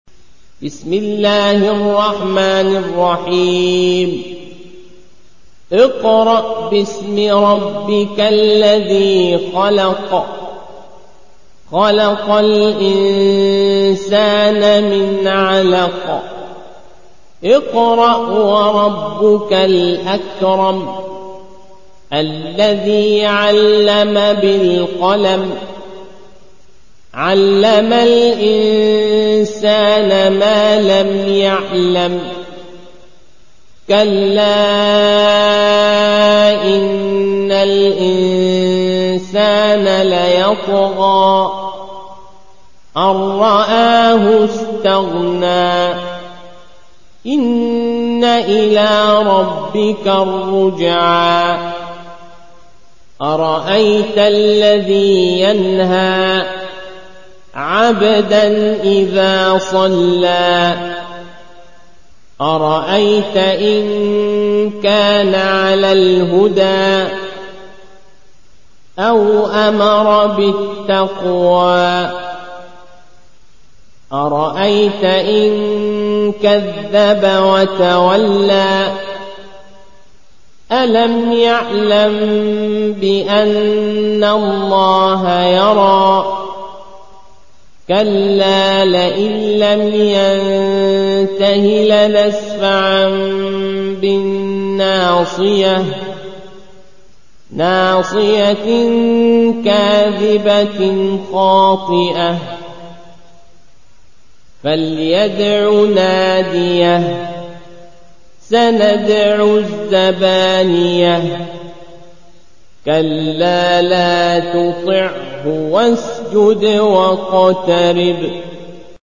روایت حفص از عاصم